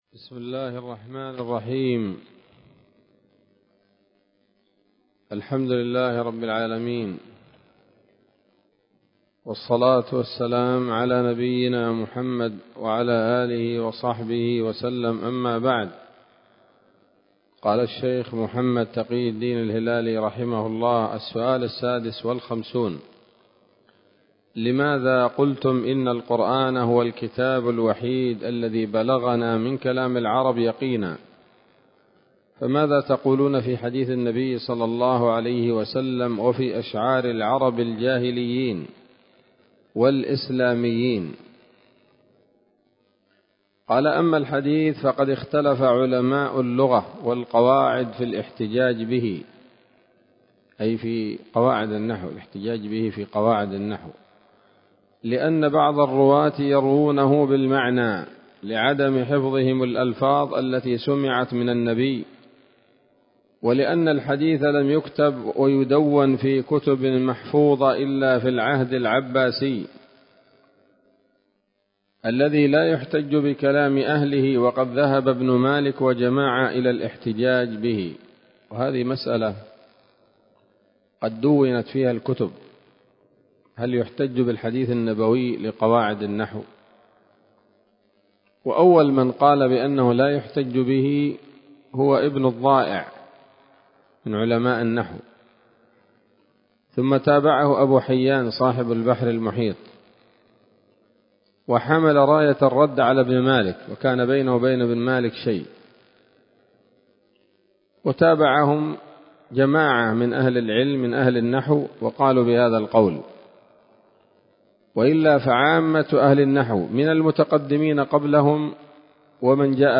الدرس الثامن عشر من كتاب نبذة من علوم القرآن لـ محمد تقي الدين الهلالي رحمه الله